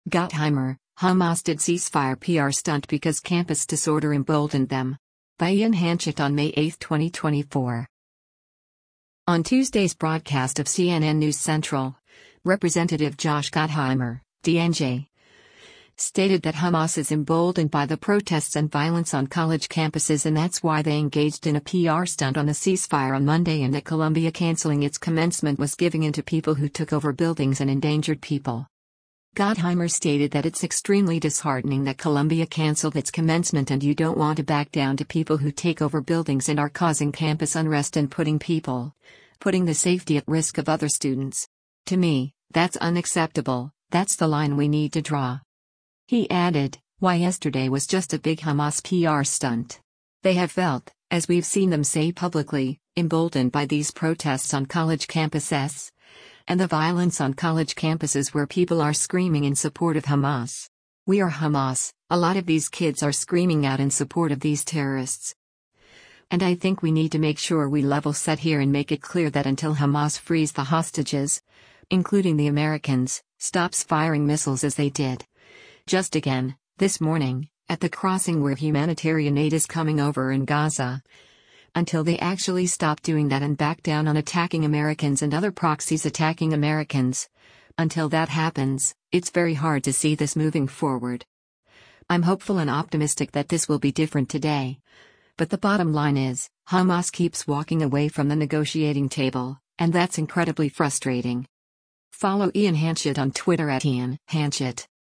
On Tuesday’s broadcast of “CNN News Central,” Rep. Josh Gottheimer (D-NJ) stated that Hamas is “emboldened” by the protests and violence on college campuses and that’s why they engaged in a “PR stunt” on a ceasefire on Monday and that Columbia canceling its commencement was giving in to people who took over buildings and endangered people.